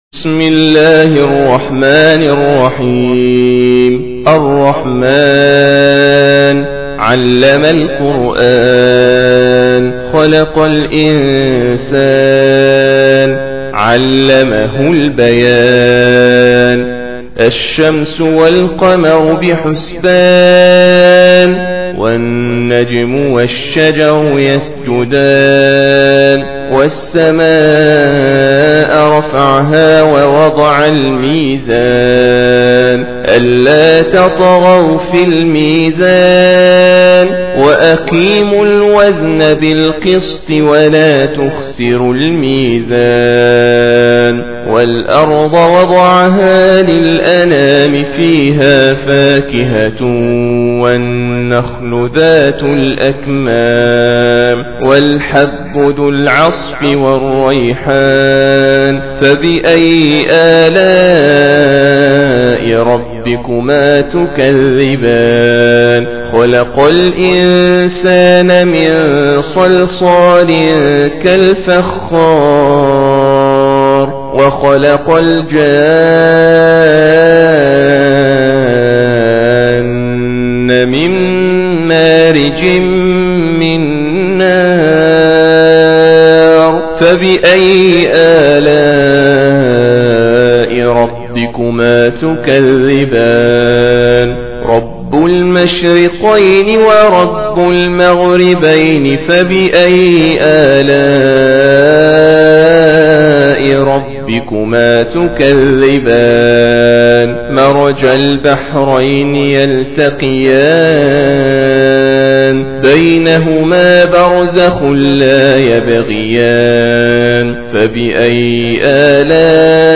55. Surah Ar-Rahm�n سورة الرحمن Audio Quran Tarteel Recitation
Surah Sequence تتابع السورة Download Surah حمّل السورة Reciting Murattalah Audio for 55.